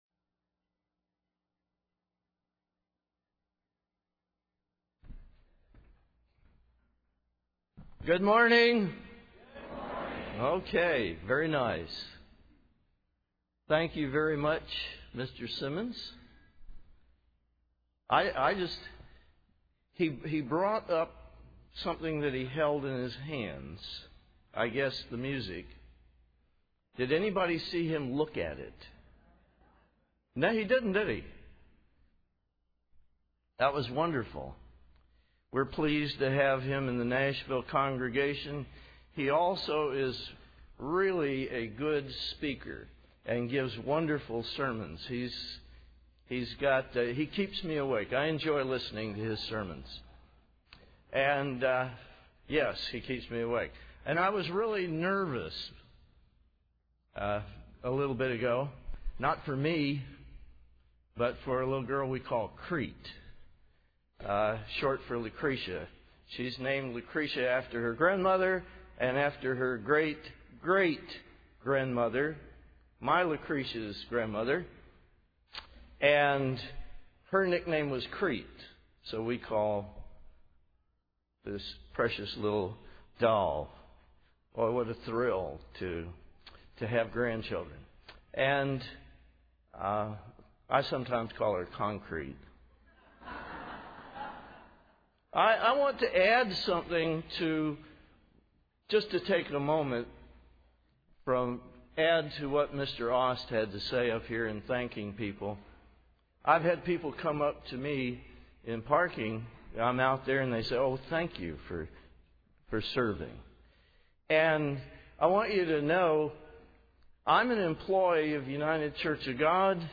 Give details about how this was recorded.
This sermon was given at the Panama City Beach, Florida 2013 Feast site.